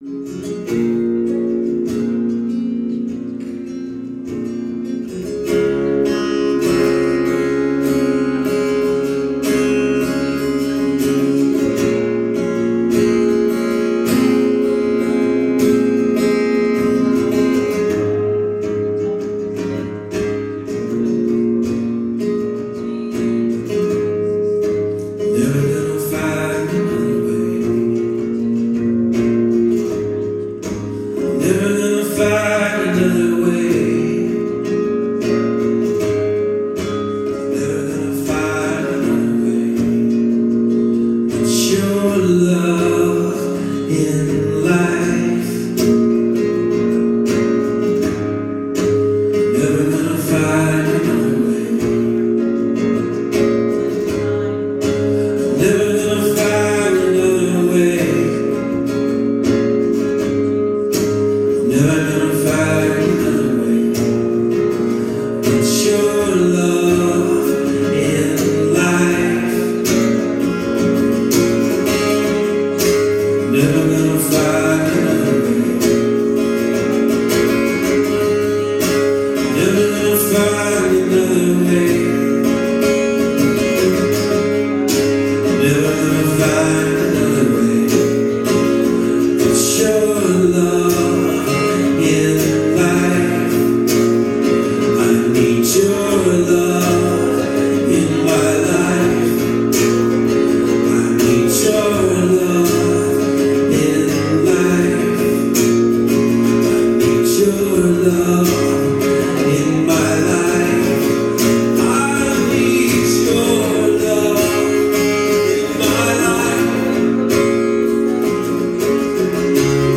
Live Worship Audio December 2025 | Hilltop Christian Fellowship
Click the play button below for a 23 minute live worship audio.
hilltop_live_worship_dec2025_2.mp3